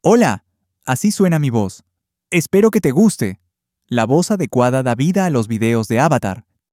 🌍 Multilingual👨 Мужской
Пол: male